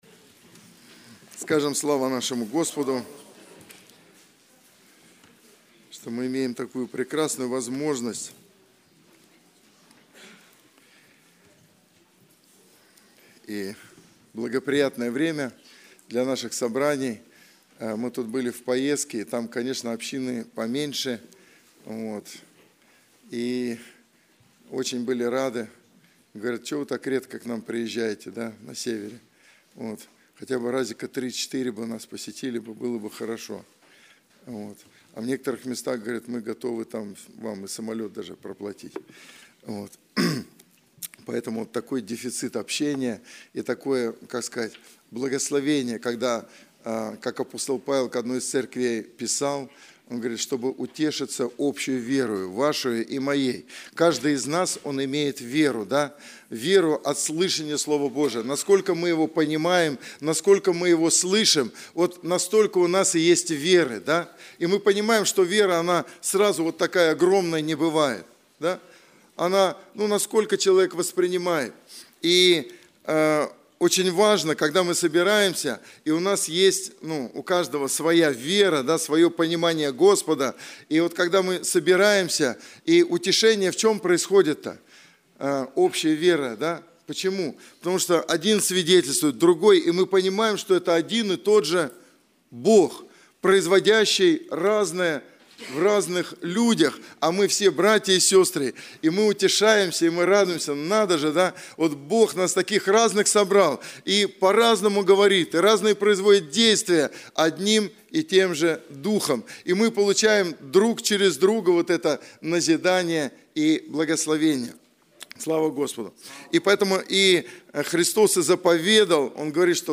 Богослужение 11.02.2024
Проповедь